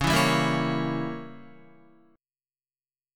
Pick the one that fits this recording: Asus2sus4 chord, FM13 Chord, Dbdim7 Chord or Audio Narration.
Dbdim7 Chord